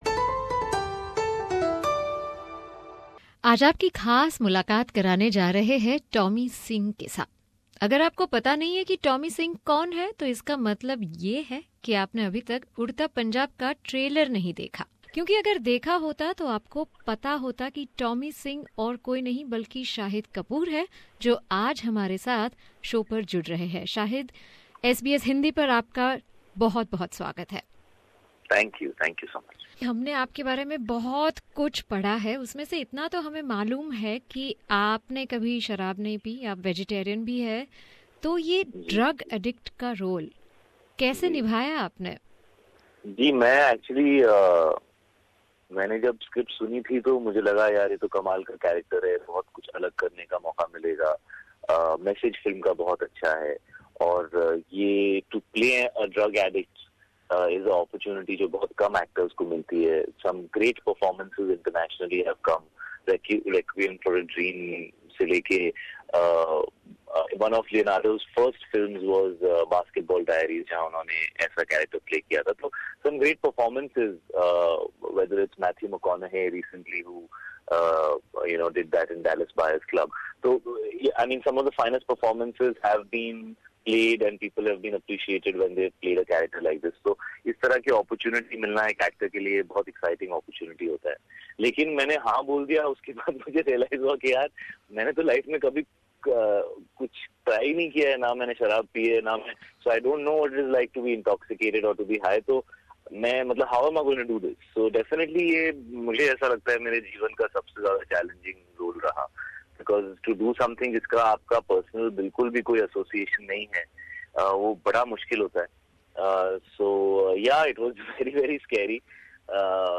SBS Hindi caught up with Shahid Kapoor before the film’s release where Shahid opened up about his character Tommy Singh in the film.